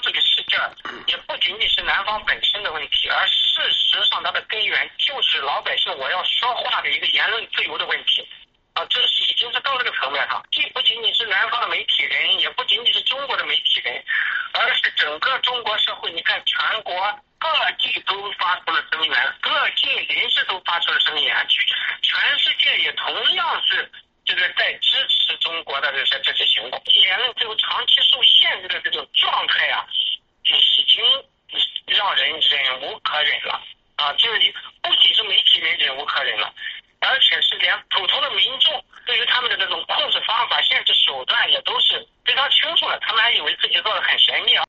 陈光诚在接受美国之音电话采访时评论南周事件所得到的广泛支持和关注。